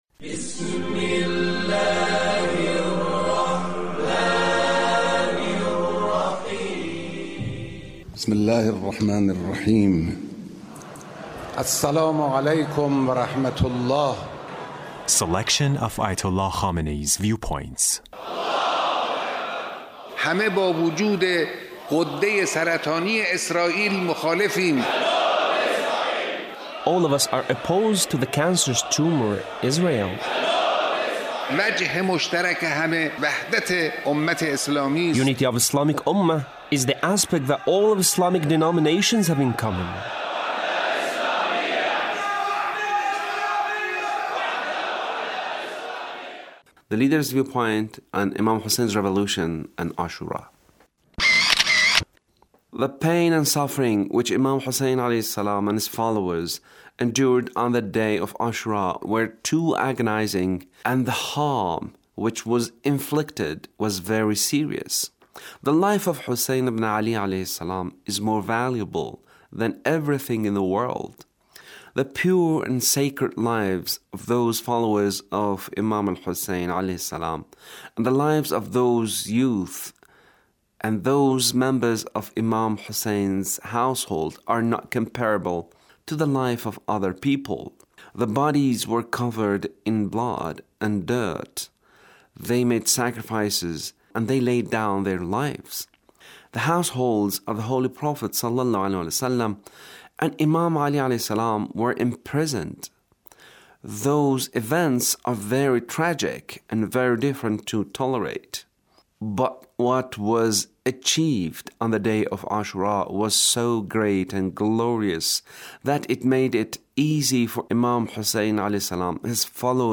Leader's speech (1472)